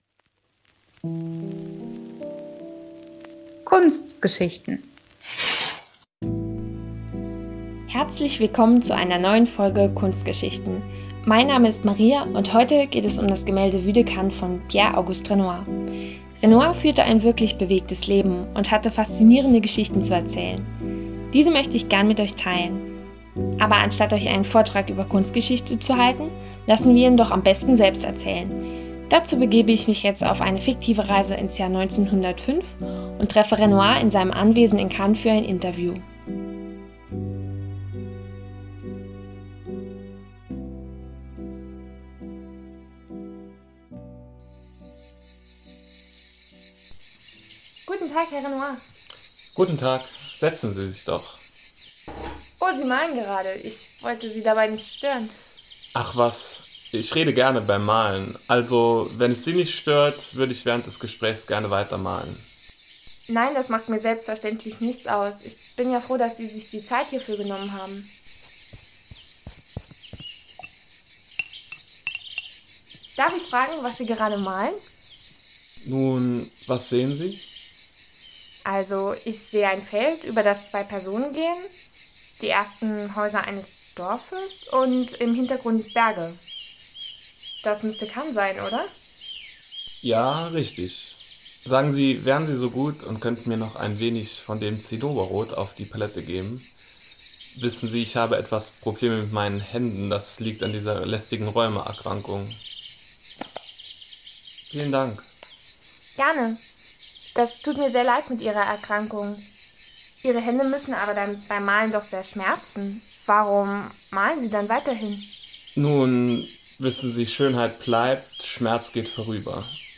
Pierre-Auguste Renoir, einer der bedeutendsten französischen Maler und Mitbegründer des Impressionismus, schaut auf sein bewegtes Leben zurück. In einem fiktiven Interview erzählt er von Ereignissen und besonderen Begegnungen aus seinem Leben.